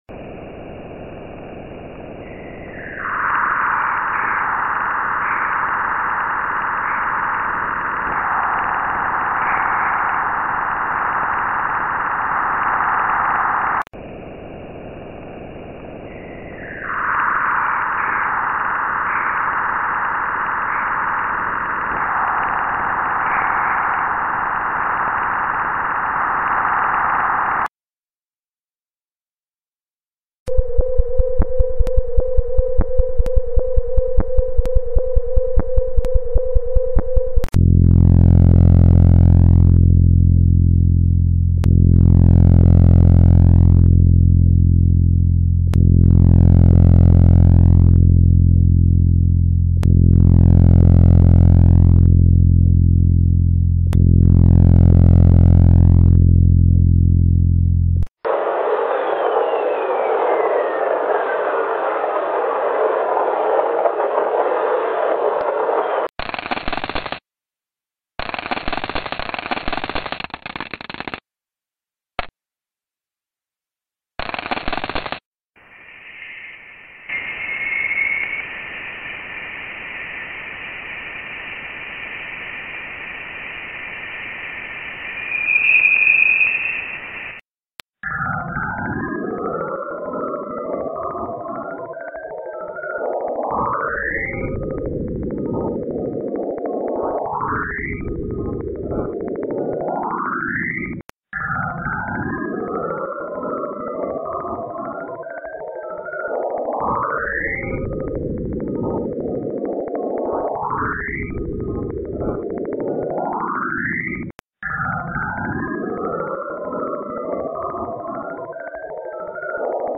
NASA ПРЕДЛОЖИЛО ПОСЛУШАТЬ ЖУТКИЕ ЗВУКИ ИЗ КОСМОСА (АУДИО)
NASA_разместило_аудиозаписи_с_жуткими_звуками_из_космоса.mp3